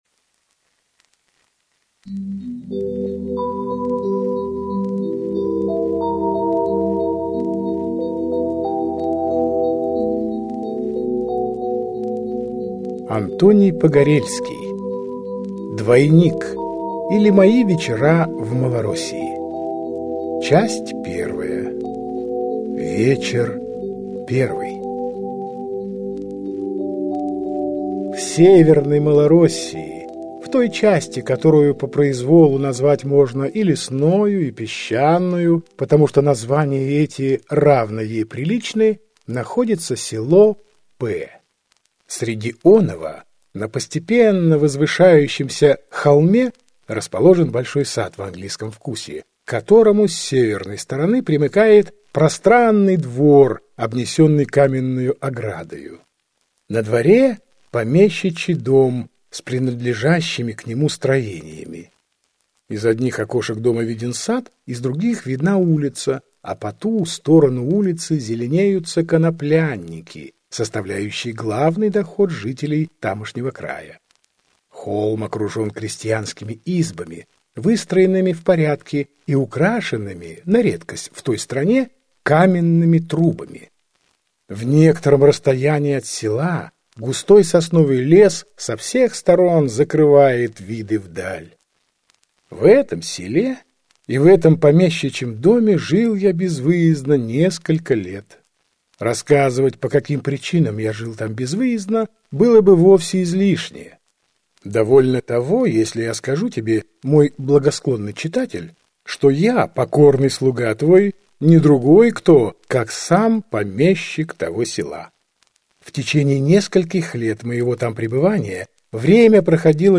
Аудиокнига Двойник, или Мои вечера в Малороссии | Библиотека аудиокниг